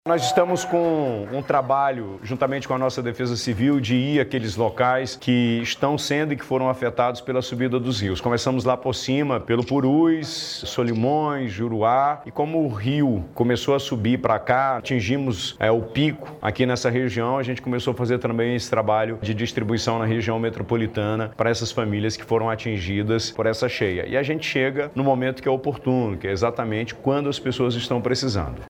Durante a entrega, na comunidade São José, uma das mais afetadas pela cheia, no município, o governador Wilson Lima disse que as ações são executadas de acordo com um cronograma feito pela Defesa Civil.